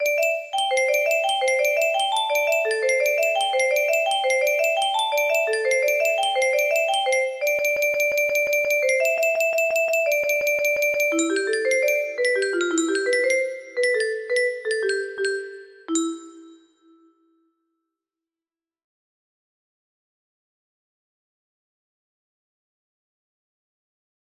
As music box melody